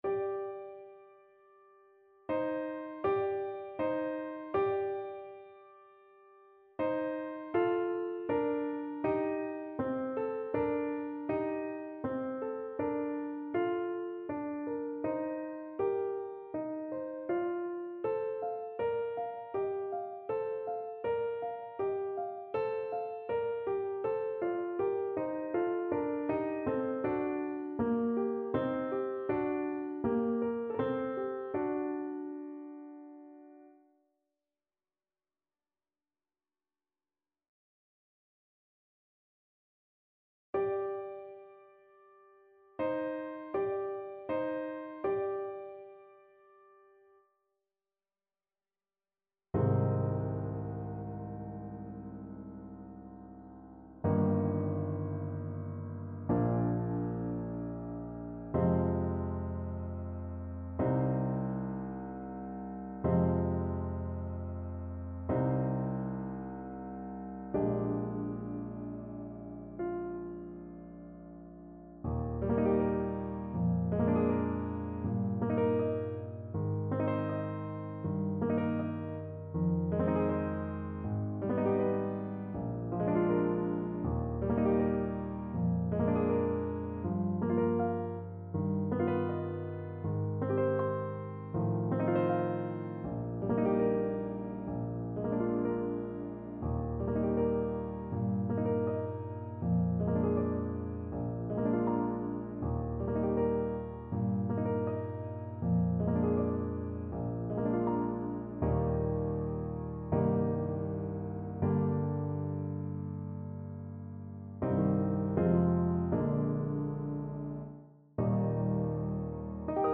Play (or use space bar on your keyboard) Pause Music Playalong - Piano Accompaniment Playalong Band Accompaniment not yet available transpose reset tempo print settings full screen
G minor (Sounding Pitch) A minor (Clarinet in Bb) (View more G minor Music for Clarinet )
Andantino = c.80 (View more music marked Andantino)
Classical (View more Classical Clarinet Music)